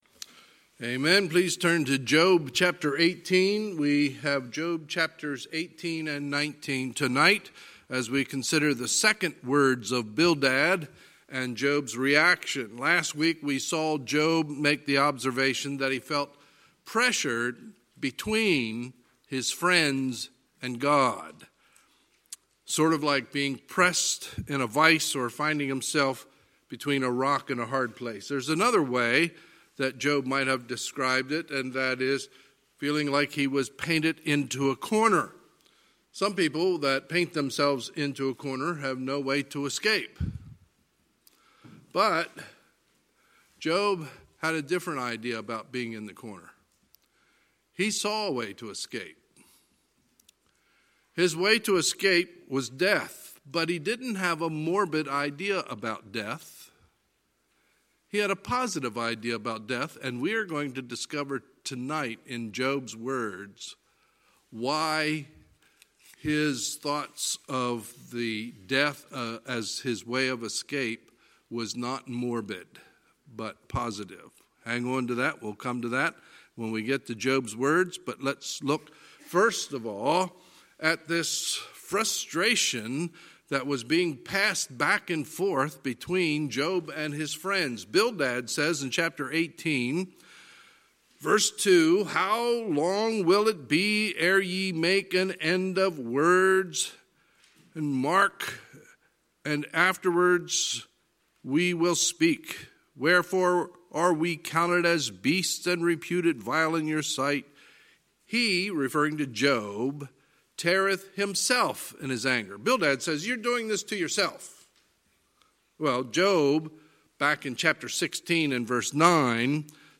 Sunday, February 23, 2020 – Sunday Evening Service